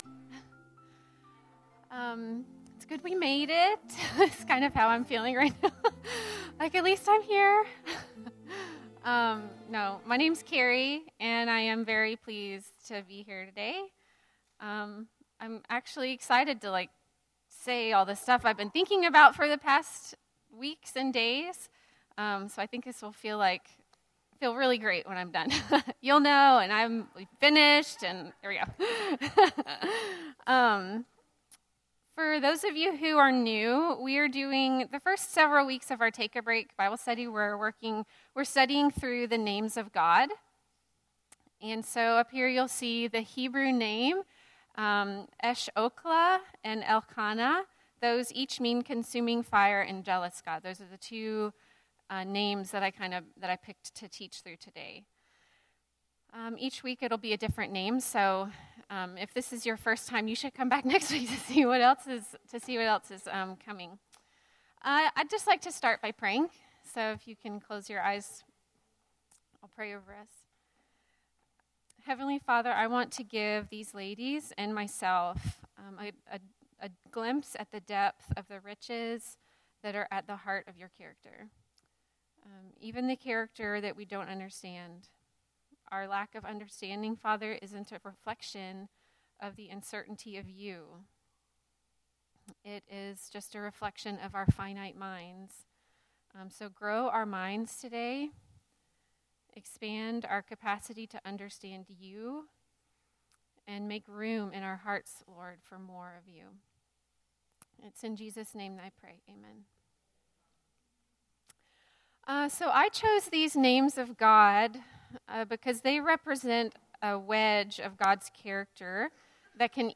TAB (Ladies Group) Talks